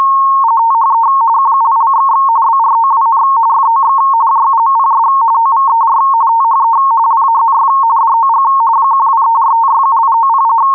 Here are a few sound samples of the transmission modes supported by gMFSK.
RTTY (USB, amateur standard 45.45 baud, 170 Hz shift) 231
rtty.wav